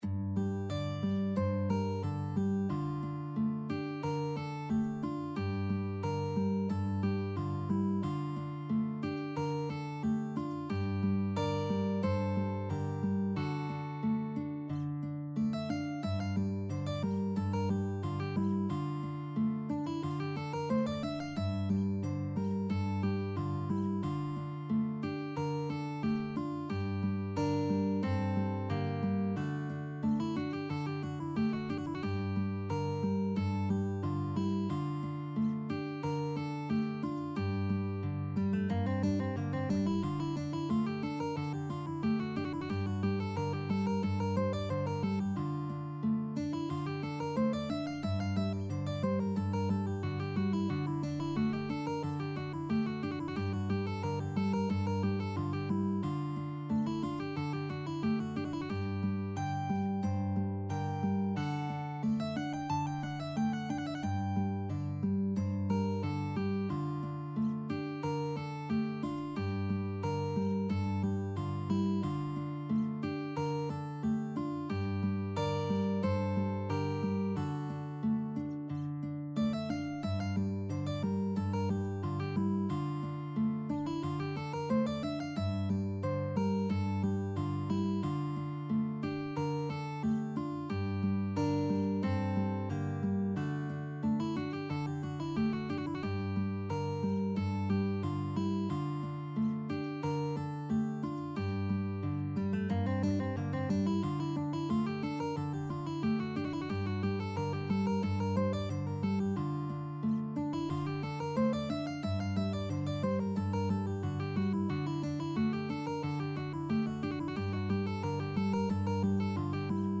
Anonymous early renaissance piece.
2/2 (View more 2/2 Music)
A minor (Sounding Pitch) (View more A minor Music for Mandolin-Guitar Duet )
Mandolin-Guitar Duet  (View more Intermediate Mandolin-Guitar Duet Music)
Classical (View more Classical Mandolin-Guitar Duet Music)